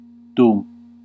Ääntäminen
IPA : /waɪl/